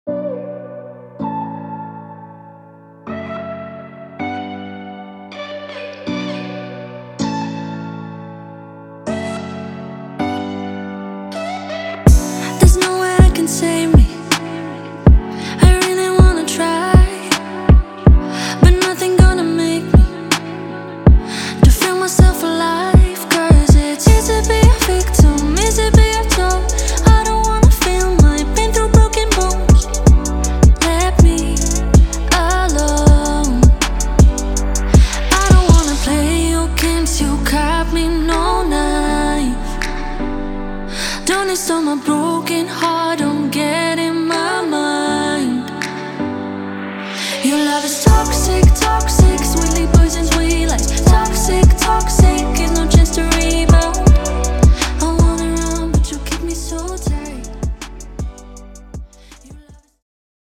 highly emotional and moving sample pack